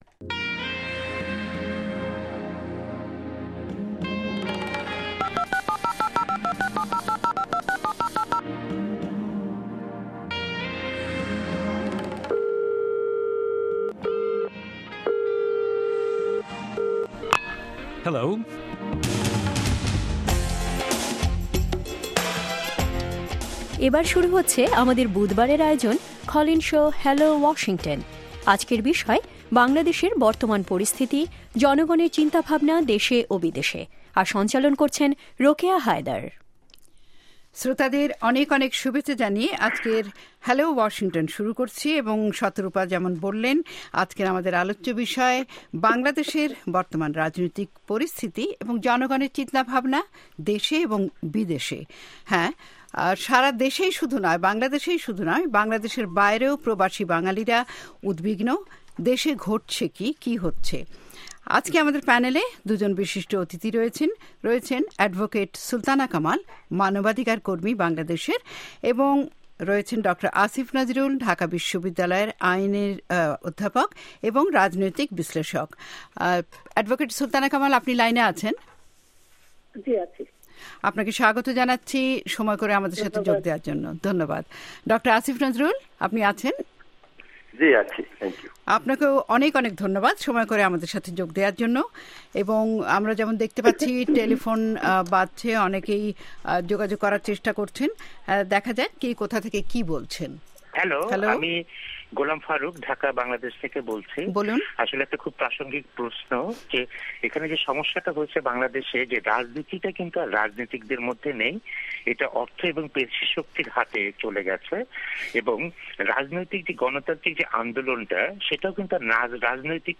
এবার শুনুন কল ইন শো